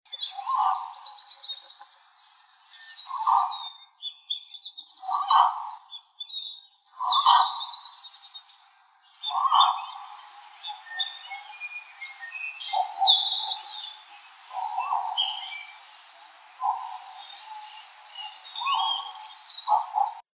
Dusky-legged Guan (Penelope obscura)
Life Stage: Adult
Location or protected area: Delta del Paraná
Condition: Wild
Certainty: Photographed, Recorded vocal